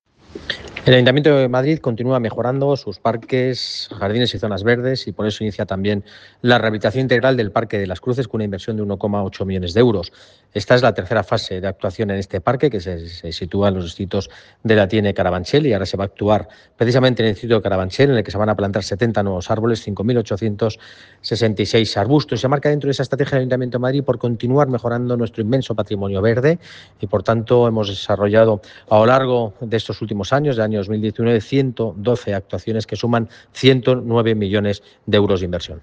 Nueva ventana:El delegado de Urbanismo, Medio Ambiente y Movilidad, Borja Carabante